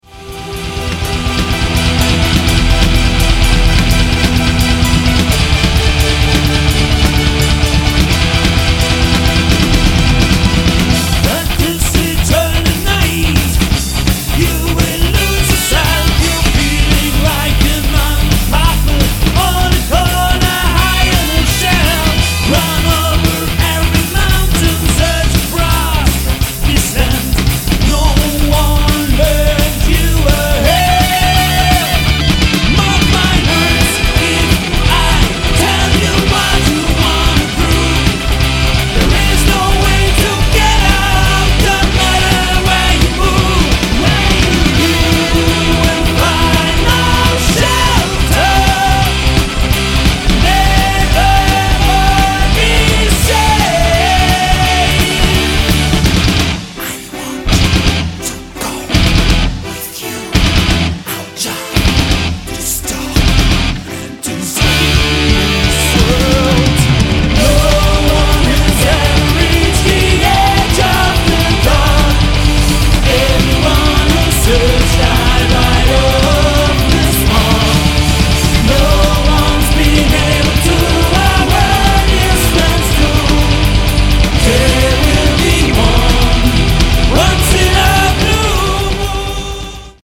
Rock und Metal
kraftvollem und melodiösen Sound